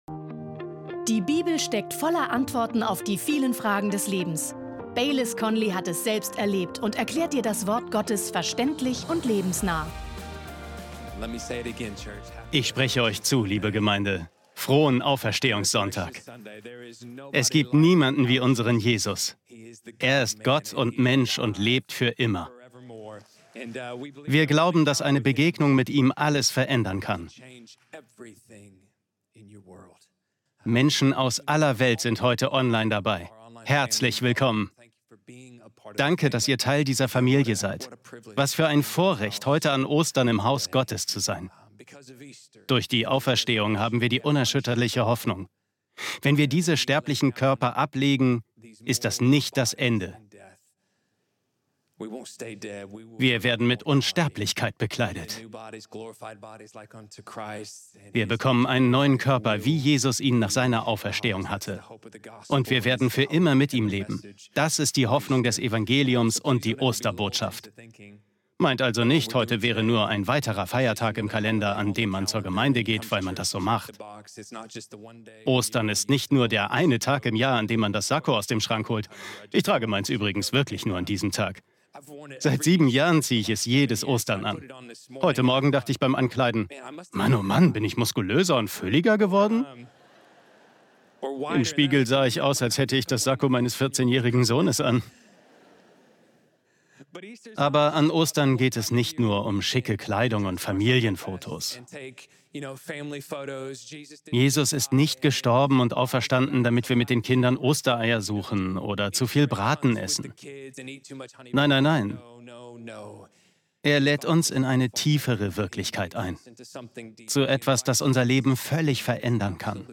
Beschreibung vor 2 Tagen Ostern ist mehr als eine Glaubenswahrheit: Ostern ist eine Realität, die du erleben kannst! In seiner kraftvollen Predigt